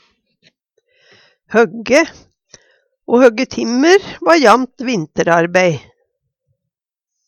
høgge - Numedalsmål (en-US)